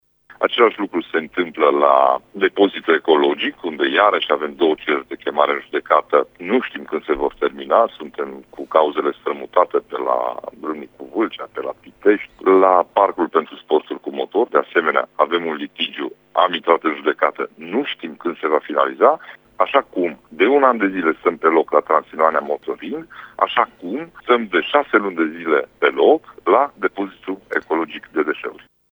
Ciprian Dobre a declarat, pentru RTM, că acest litigiu se putea soluționa fără suspendarea lucrărilor: